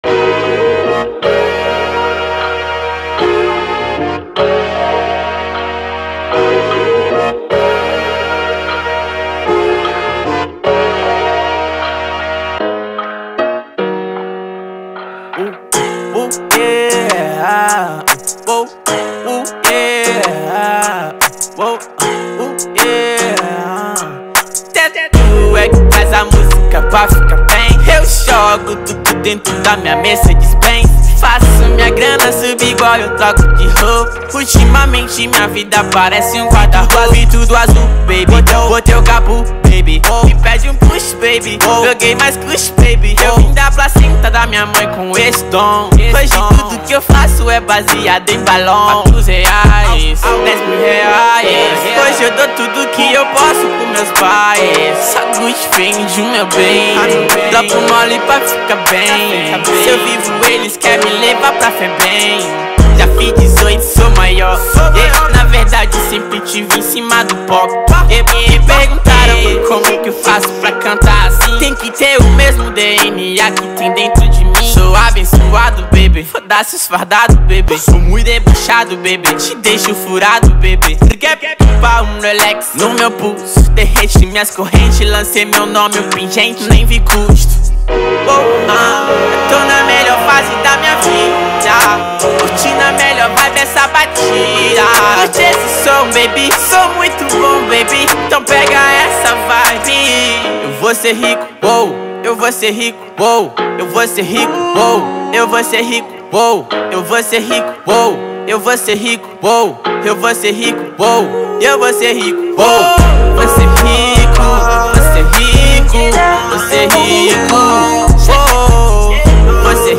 2025-01-27 01:58:23 Gênero: Trap Views